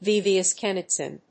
音節vìv・i・séc・tion・ist 発音記号・読み方
/‐ʃ(ə)nɪst(米国英語)/